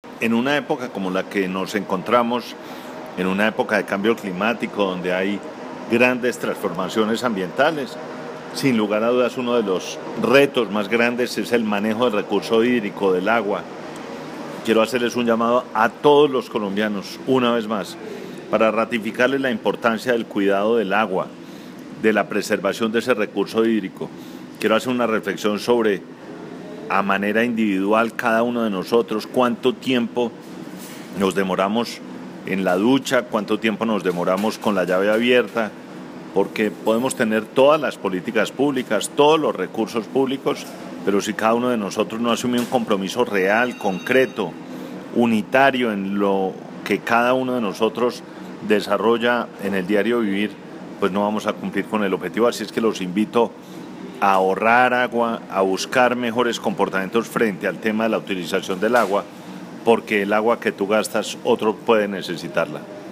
Declaraciones del Ministro de Ambiente, Gabriel Vallejo López
04audio_ministro_uso_del_agua_mp3cut.net_.mp3